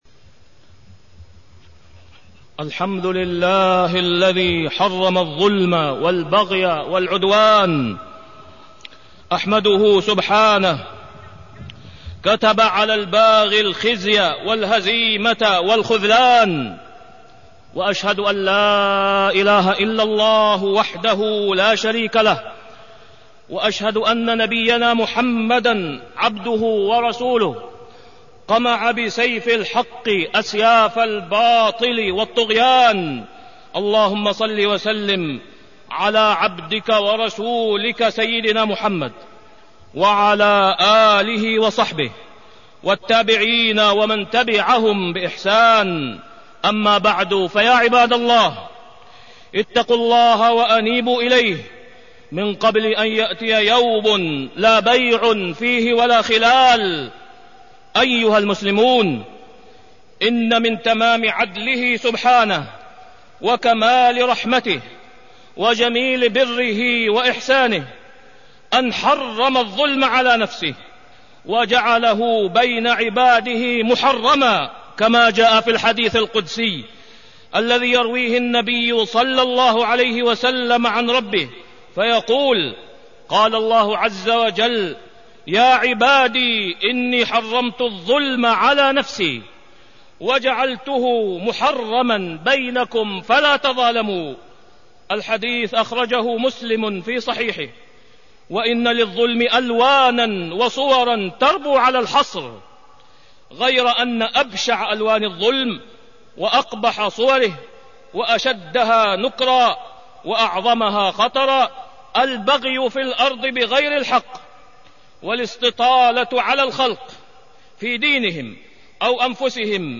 تاريخ النشر ٢٢ محرم ١٤٢٣ هـ المكان: المسجد الحرام الشيخ: فضيلة الشيخ د. أسامة بن عبدالله خياط فضيلة الشيخ د. أسامة بن عبدالله خياط تحريم الظلم